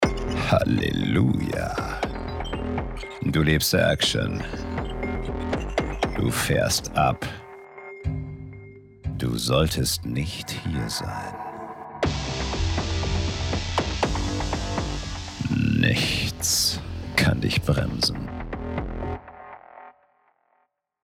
(Werbung) Du Willst Action